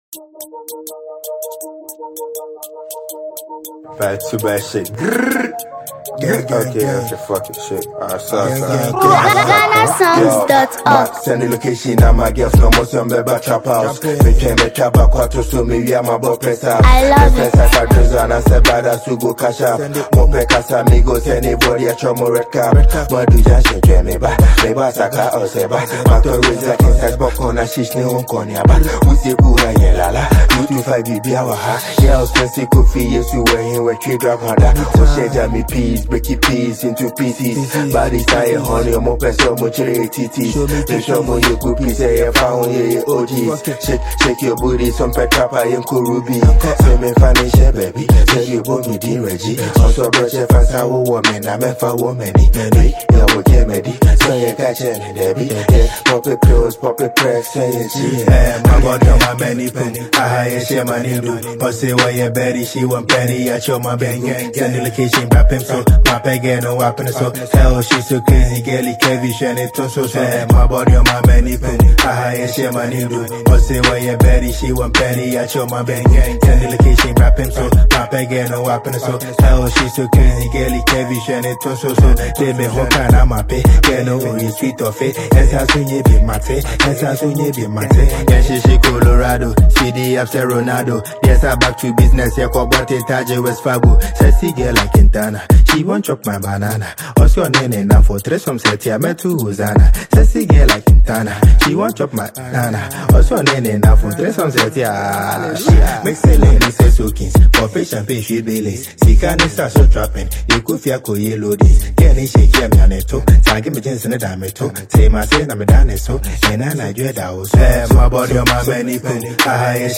energetic track
modern Ghanaian drill